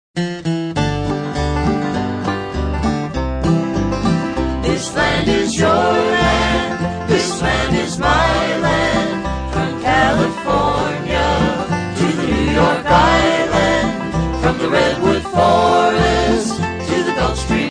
classic folk song favorites